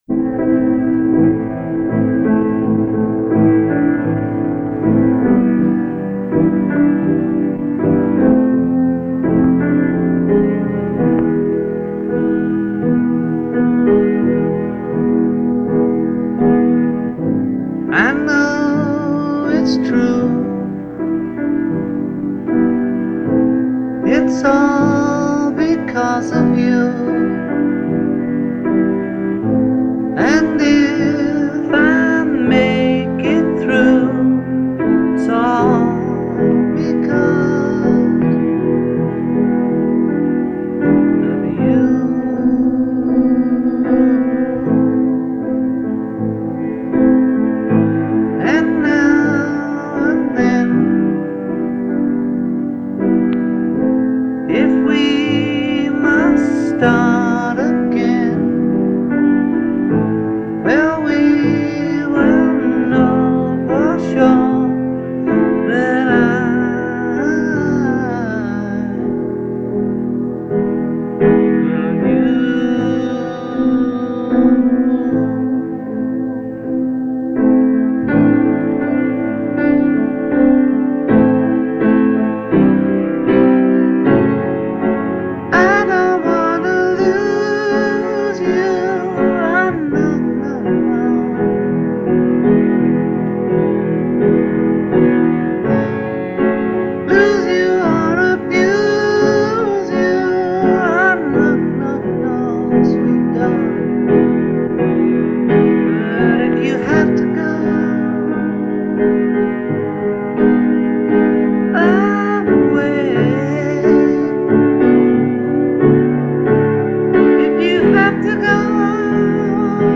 Disc 2 - Piano demos